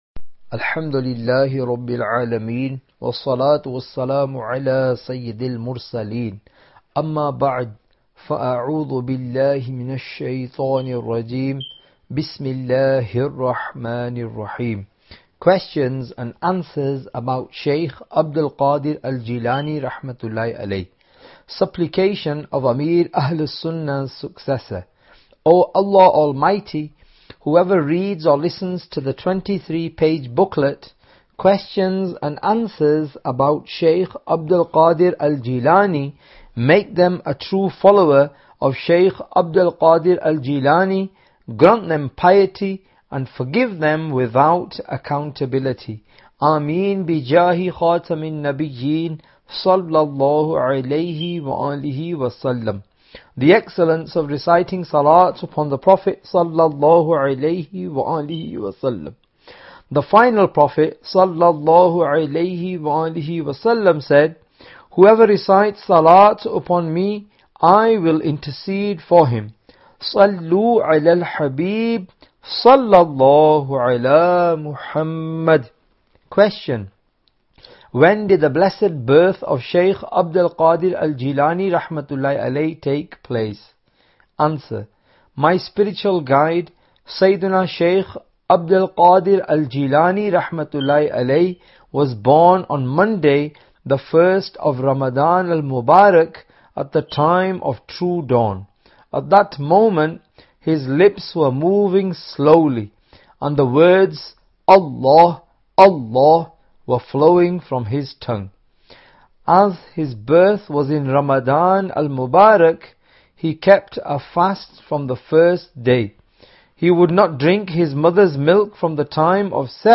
Audiobook - Questions & Answers About Sheikh Abd Al Qadir Al Jilani رَحْمَةُ الـلّٰـهِ عَلَيْه (English) Oct 25, 2022 MP3 MP4 MP4 Share Audiobook - Questions & Answers About Sheikh Abd Al Qadir Al Jilani رَحْمَةُ الـلّٰـهِ عَلَيْه